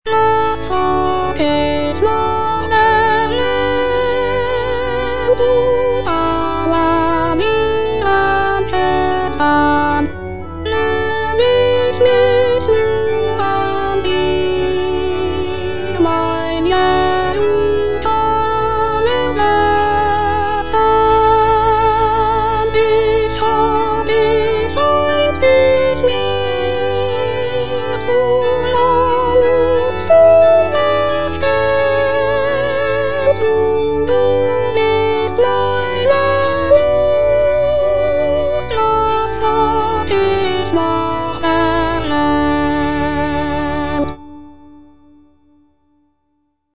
Choralplayer playing Cantata
J.S. BACH - CANTATA "SEHET, WELCH EINE LIEBE" BWV64 Was frag' ich nach der Welt - Soprano (Voice with metronome) Ads stop: auto-stop